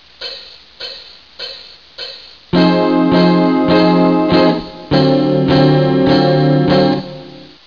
Я решил начинать с игры Eb-минорного гексатонического (Eb-F-Gb-Ab-Bb-Dd) ряда на аккорде Ebm7.